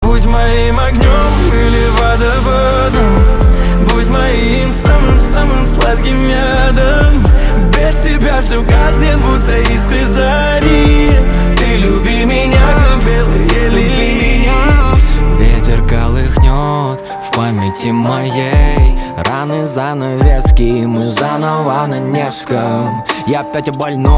• Качество: 320, Stereo
мужской голос
спокойные
пианино
романтические